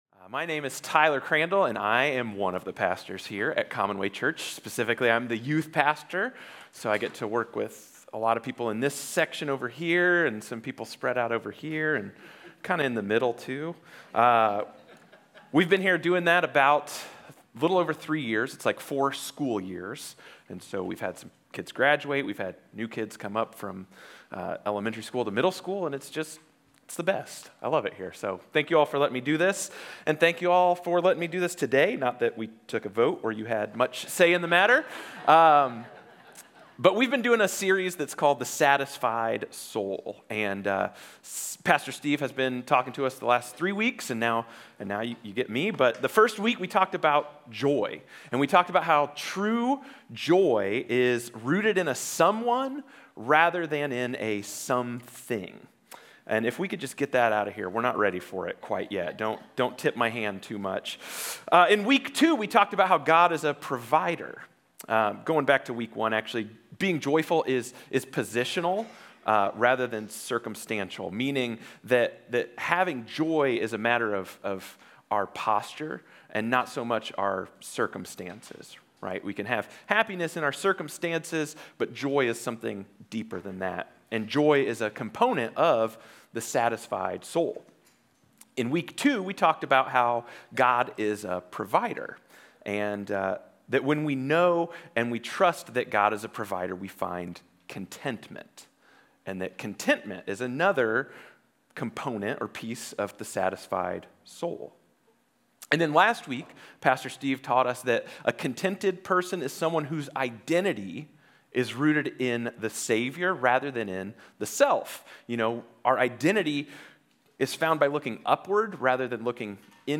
A message from the series "The Satisfied Soul."